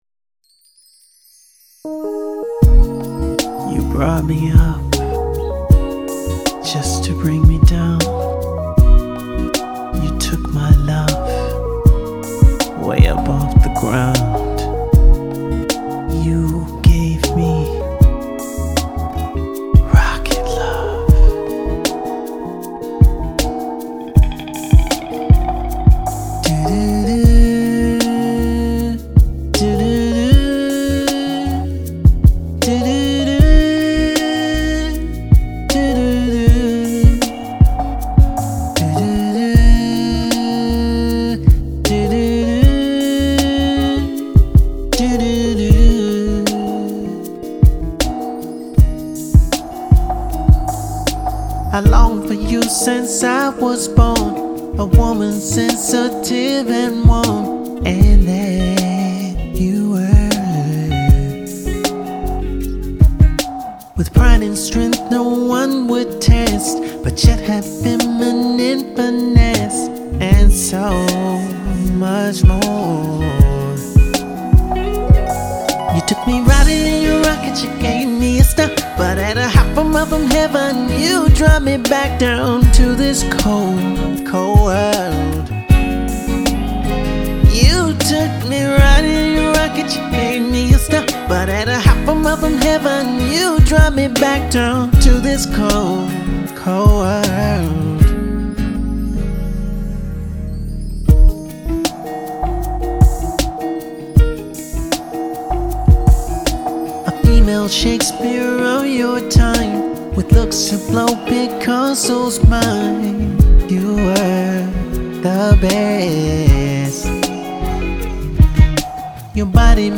rhythm and acoustic guitar